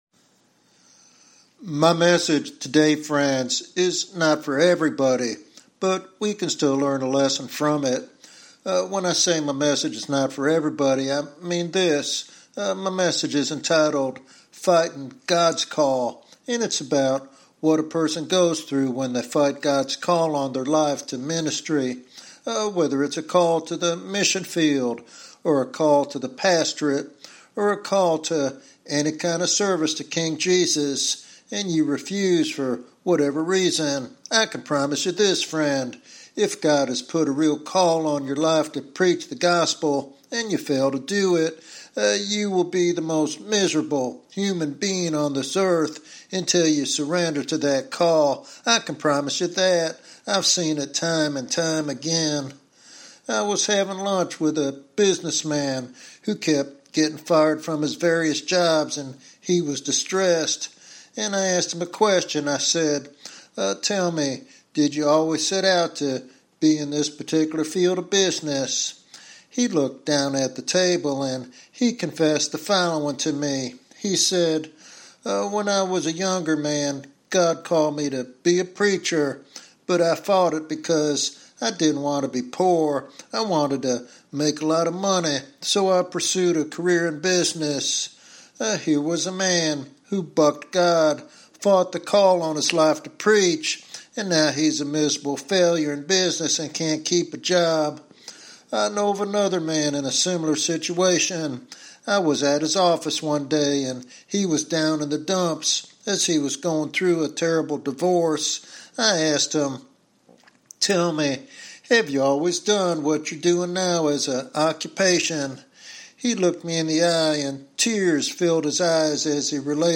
This sermon serves as a powerful reminder that true fulfillment and impact come only through surrendering to God's purpose.